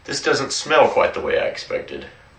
描述：要求的样本，说一个动物的名字，好像在命令一台电脑。
标签： 动物 英语 口语词 语音
声道立体声